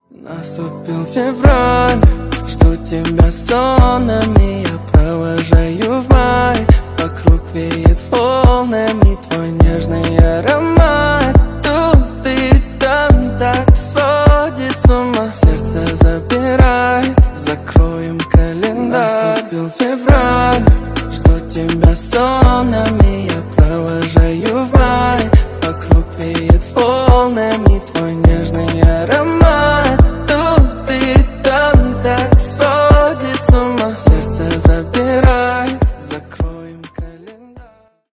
Поп Музыка
грустные # спокойные